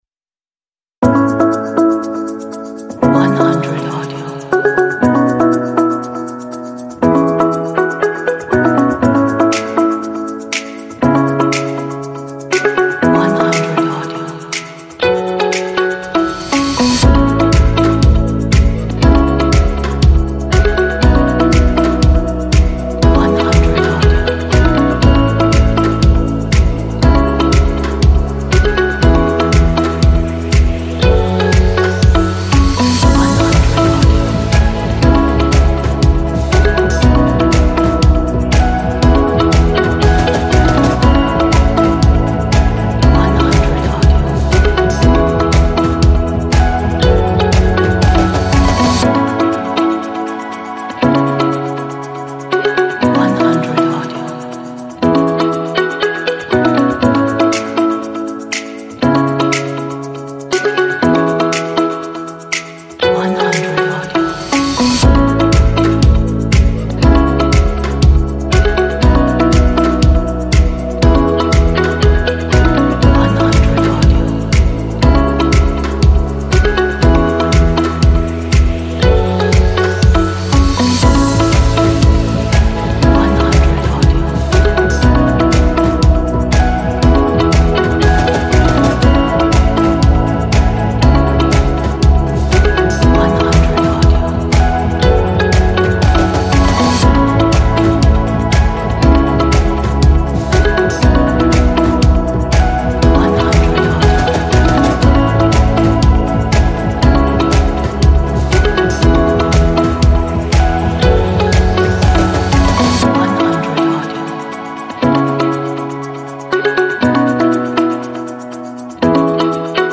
Positive, upbeat and inspiring background music track.
Is a perfect motivational track